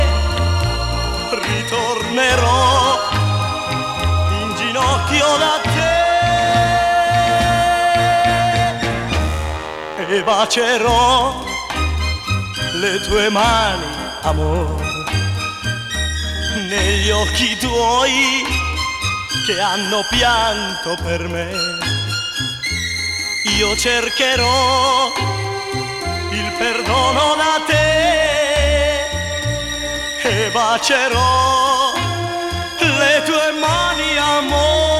Pop Rock
Жанр: Поп музыка / Рок